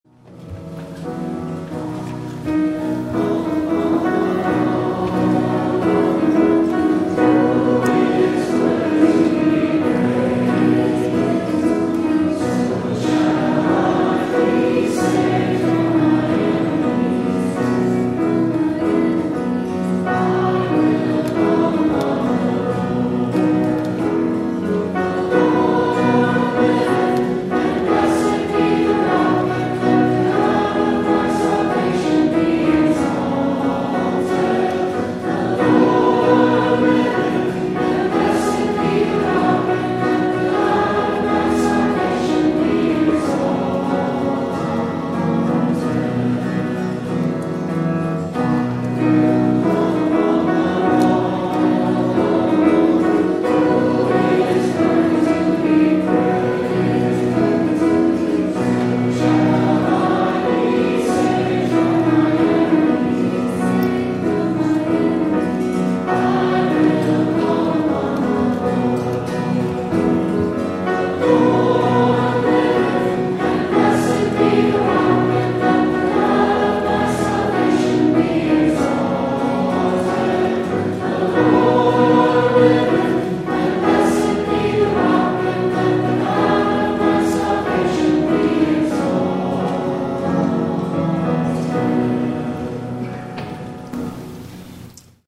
*THE CONGREGATIONAL RESPONSE